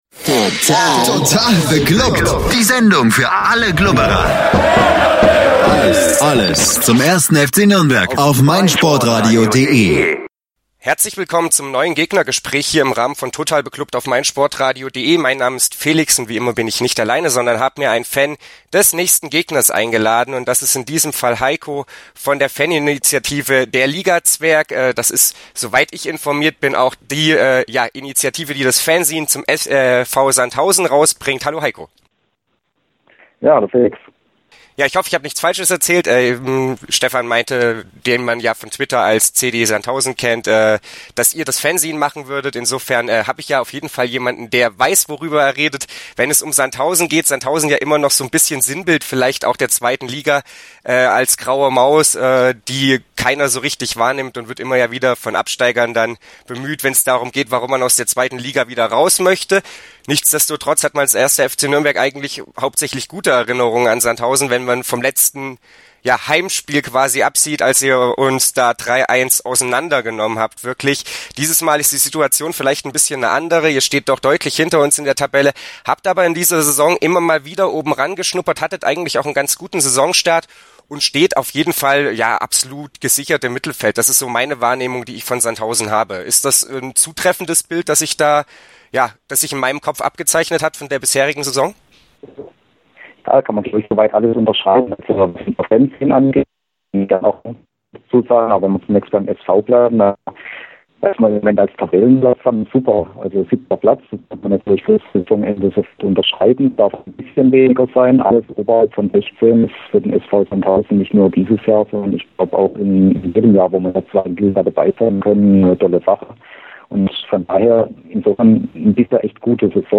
Bevor es zu Nachfragen kommt: Ja, es wurde lange gerungen und überlegt, aber letztlich entschieden das Interview trotz miserabler Tonqualität hochzuladen.
gegnergespraech-fcn-sandhausen.mp3